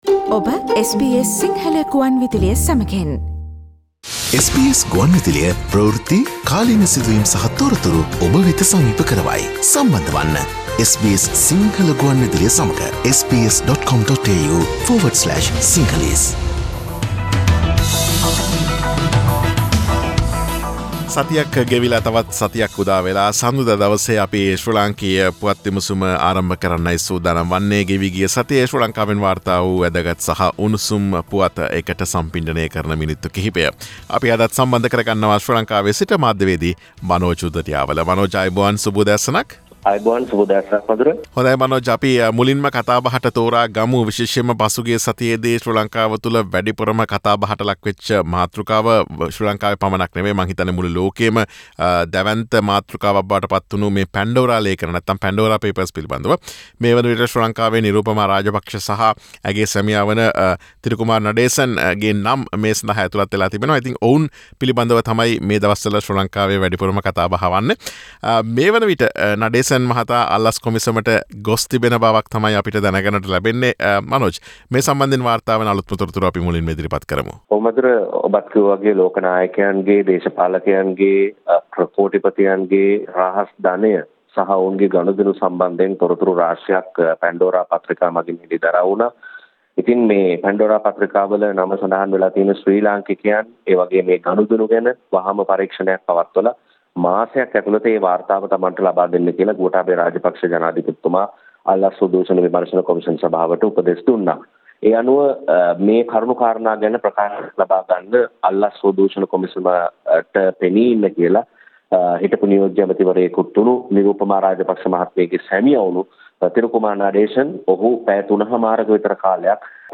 SBS Sinhala radio brings you the most prominent news highlights of Sri Lanka in this featured current affairs segment